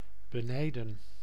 Ääntäminen
IPA: [ɑ̃.vje]